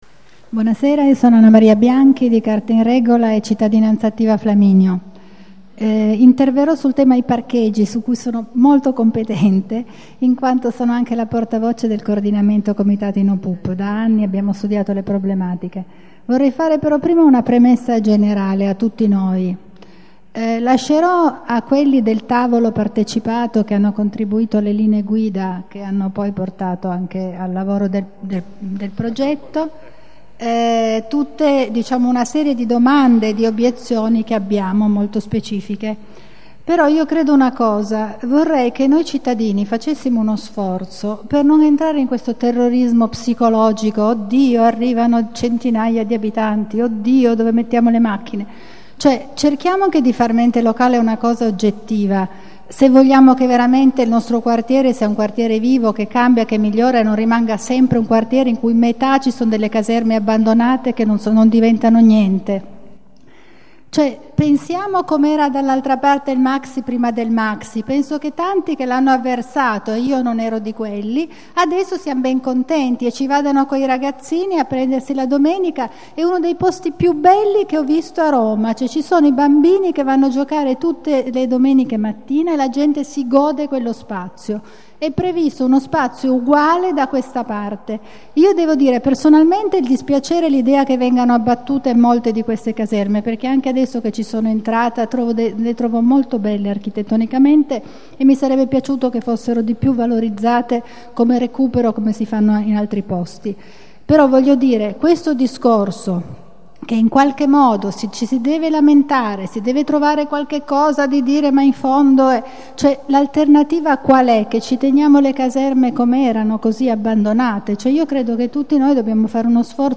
Piano di recupero del Quartiere Città della Scienza - Ascolto audio del secondo incontro partecipativo